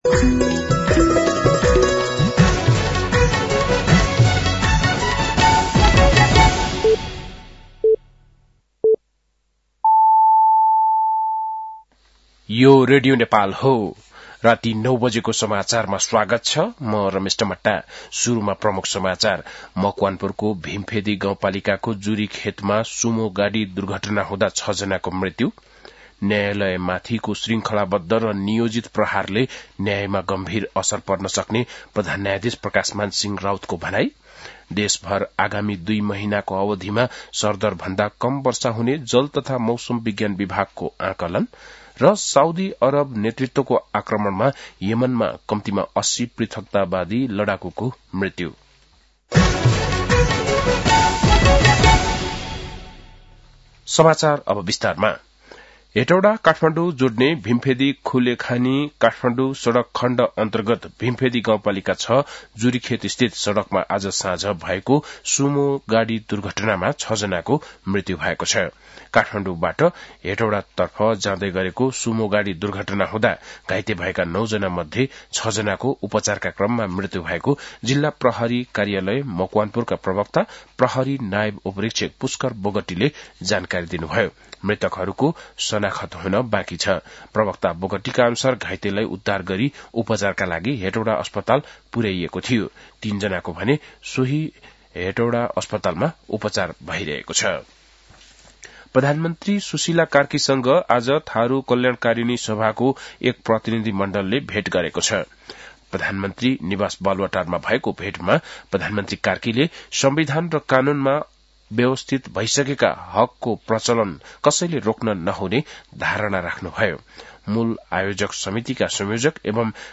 बेलुकी ९ बजेको नेपाली समाचार : २० पुष , २०८२
9-pm-nepali-news-9-20.mp3